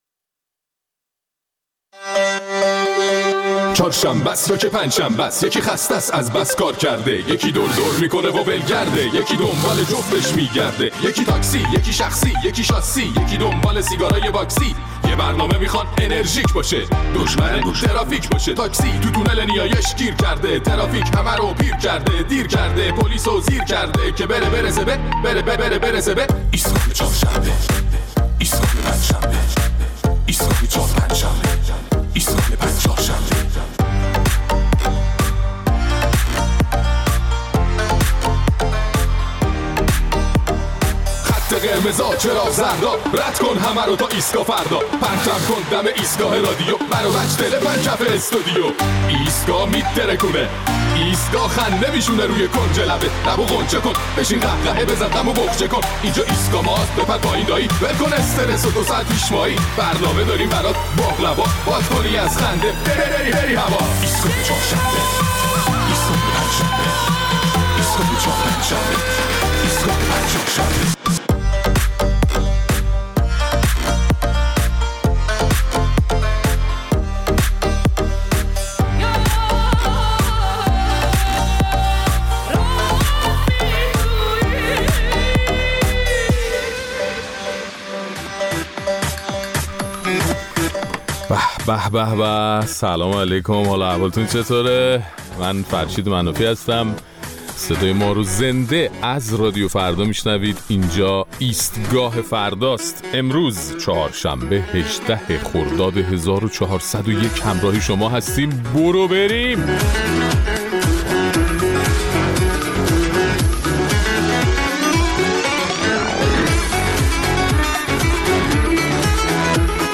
در این برنامه نظرات شنوندگان ایستگاه فردا را در مورد پیام شاهزاده رضا پهلوی و نظر علی خامنه‌ای که مردم ایران را انقلابی‌تر و دیندارتر از اول انقلاب می‌دانست، می‌شنویم.